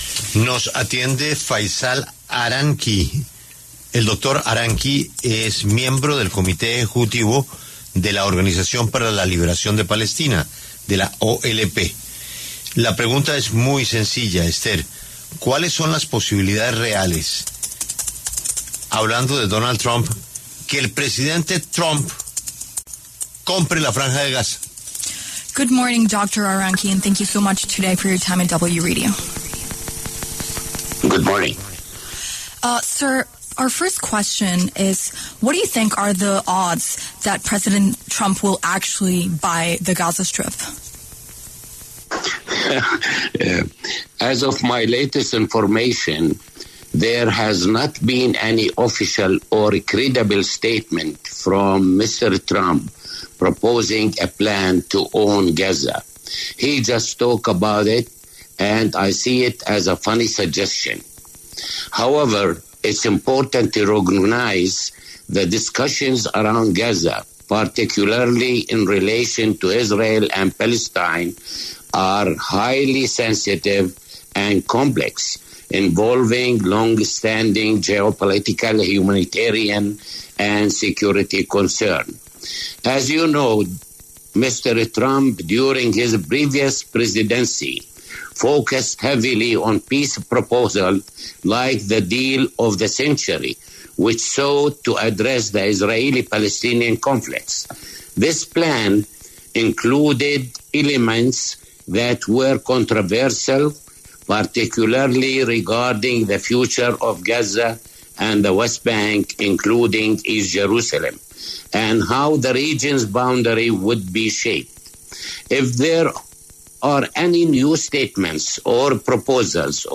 Sobre el tema, pasó por los micrófonos de La W el doctor Faisal Aranki, quien es miembro del comité ejecutivo de la Organización para la Liberación de Palestina (OLP), exponiendo sus perspectivas tras las declaraciones del mandatario norteamericano.